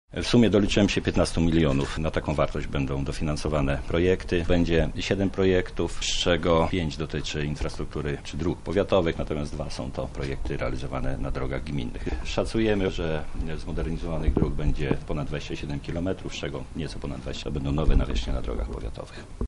Powiat lubelski także otrzyma pieniądze. Plany na ich wykorzystania omawia starosta Paweł Pikuła